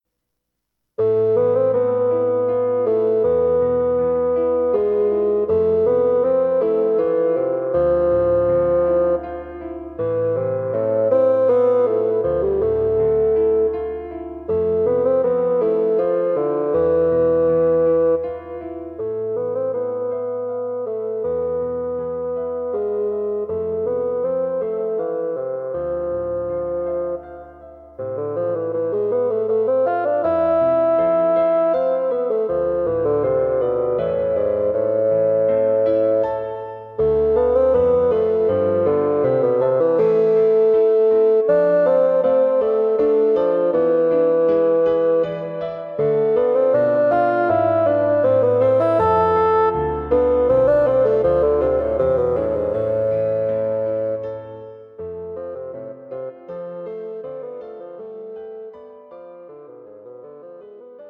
Bassoon Solo and Piano
This is more like a duet for Piano and Bassoon.